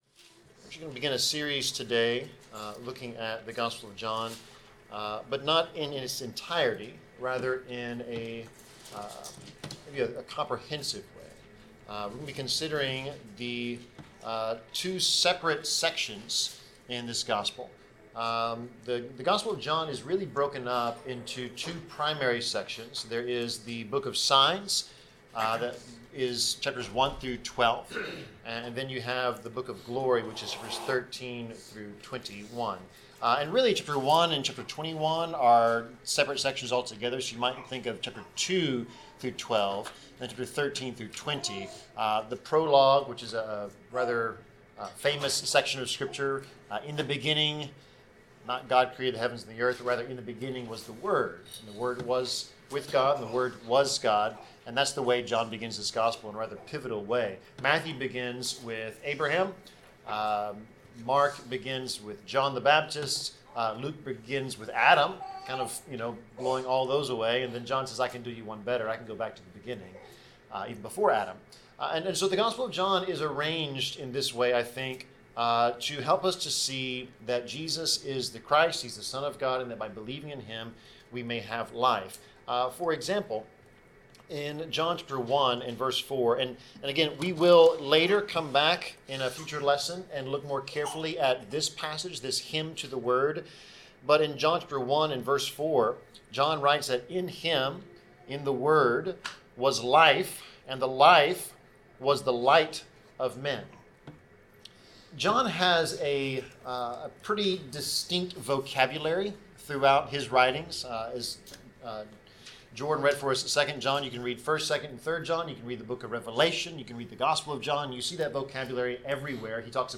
Passage: John 2 Service Type: Sermon